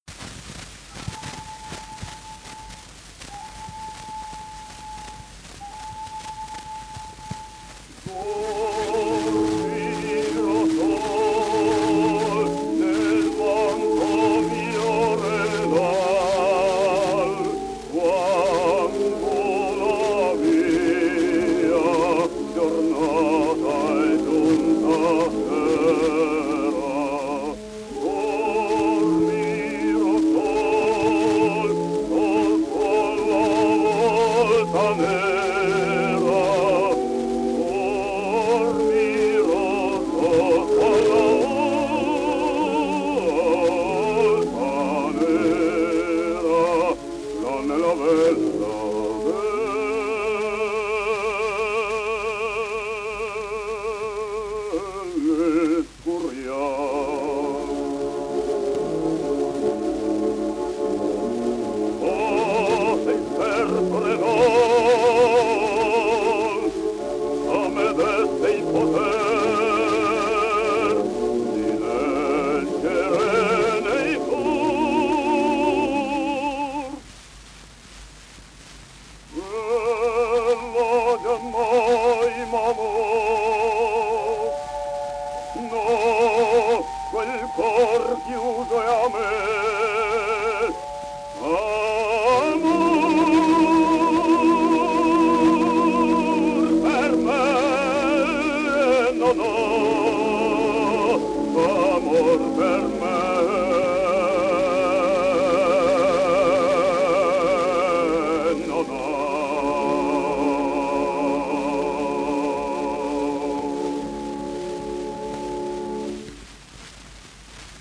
basso